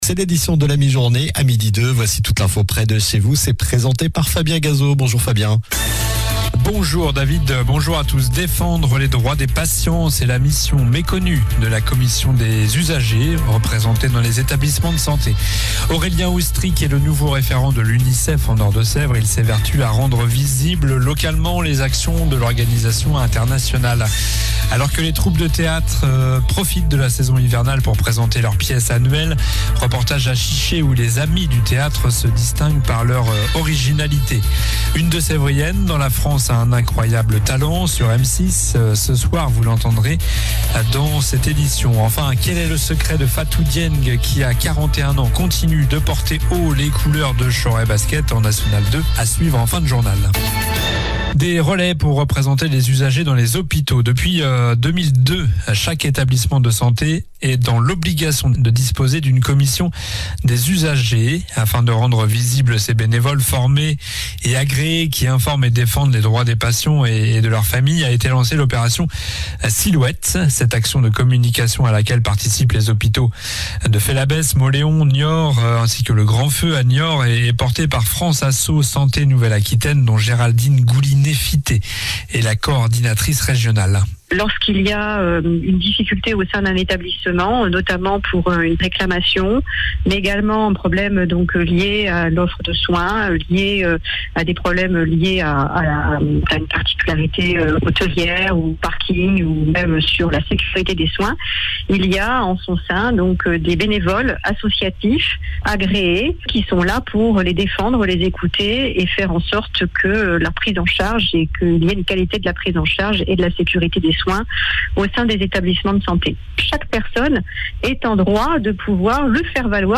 Journal du mercredi 11 décembre (midi)